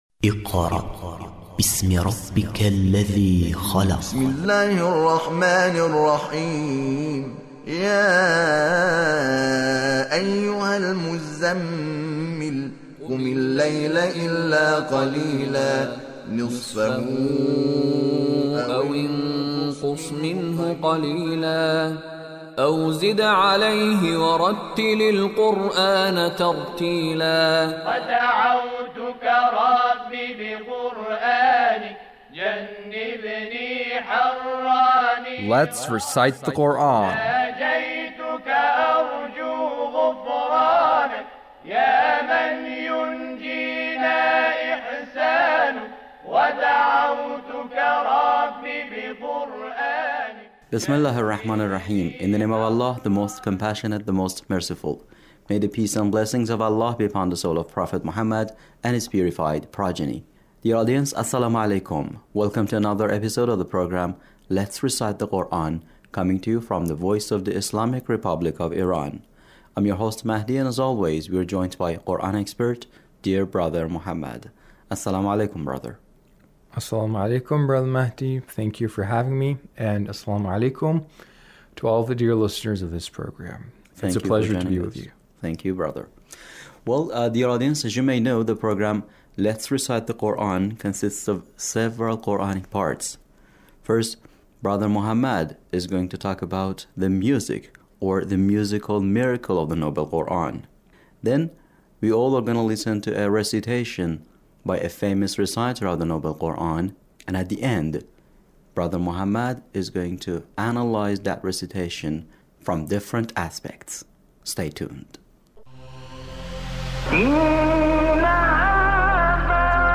Abul Ainain Shuaisha recitation